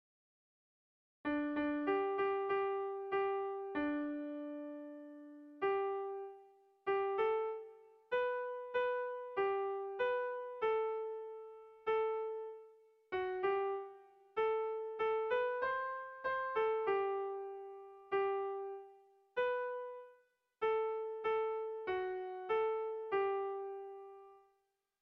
Erlijiozkoa
Lauko txikia (hg) / Bi puntuko txikia (ip)
AB